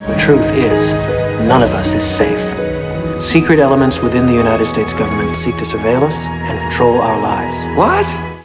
Bruce's voice